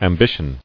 [am·bi·tion]